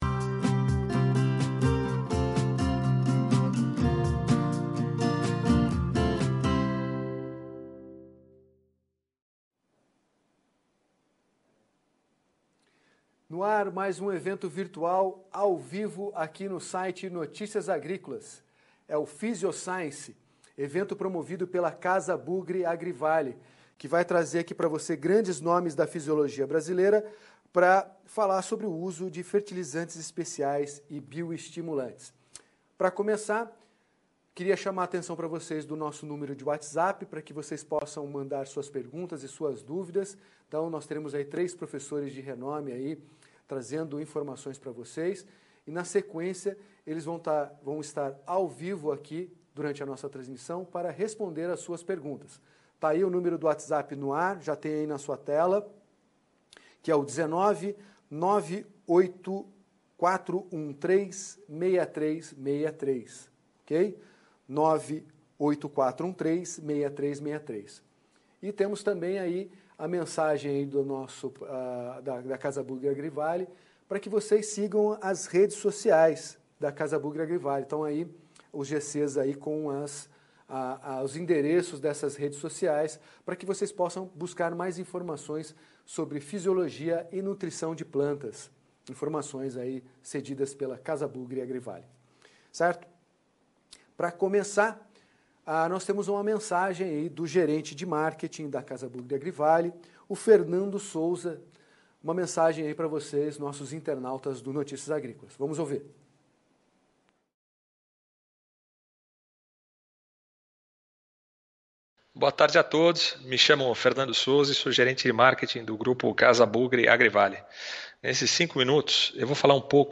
On-line, o encontro teve como objetivo reunir grandes referências da fisiologia, para abordar os recentes avanços da adoção de bioestimulantes como uma ferramenta de promoção de crescimento em plantas.